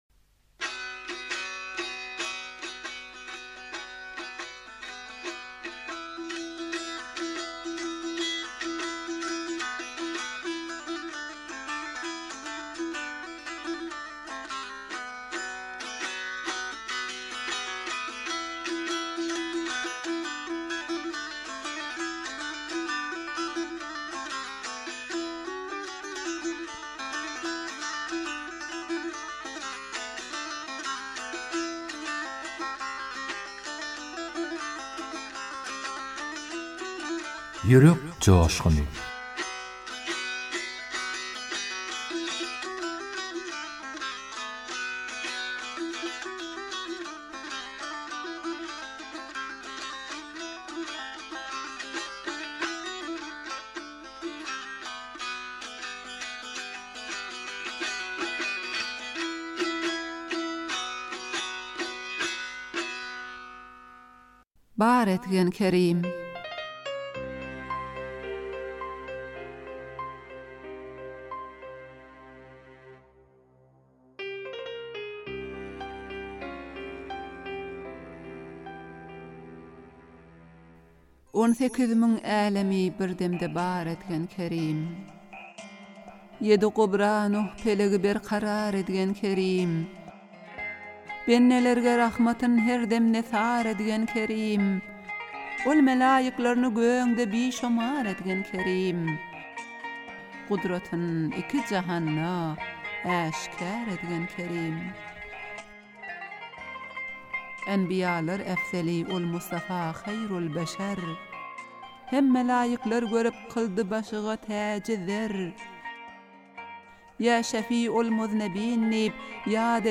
turkmen goşgy owaz aýdym